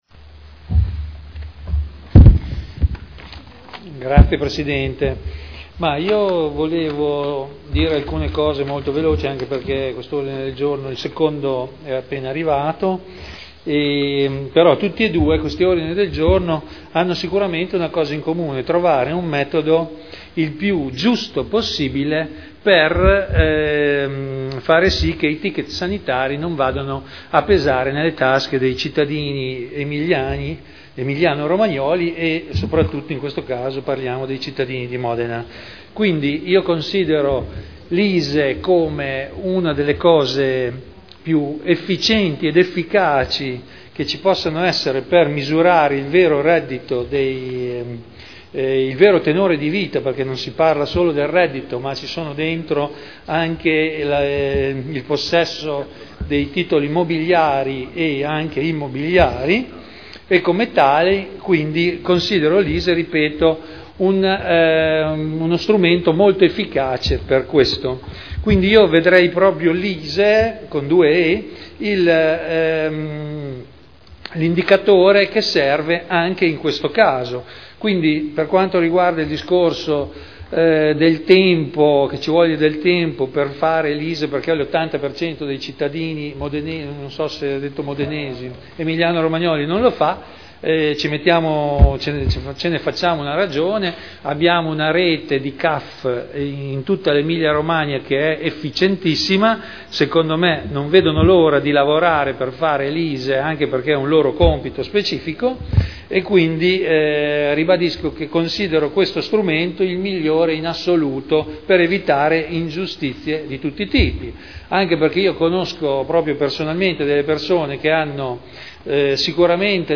Vittorio Ballestrazzi — Sito Audio Consiglio Comunale
Seduta del 12/09/2011. Dibattito su Ordini del Giorno relativi all'introduzione del ticket sanitario.